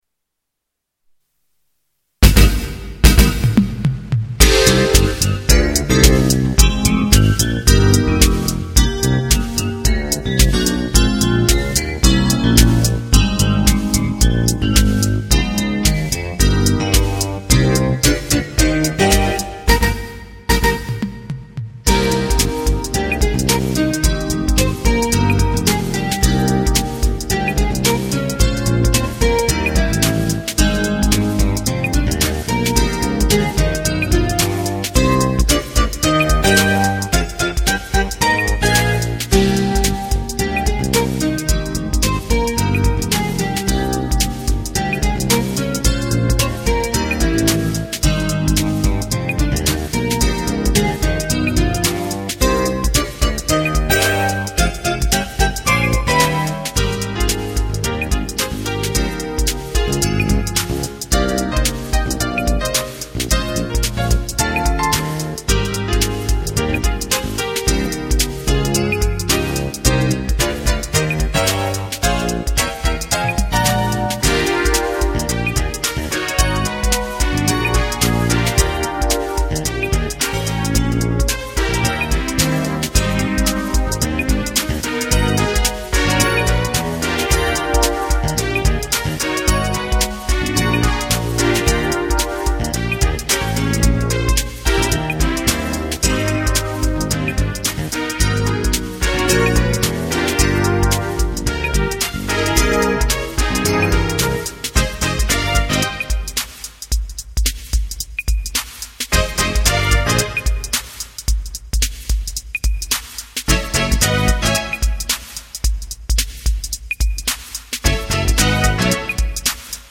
Popjazz: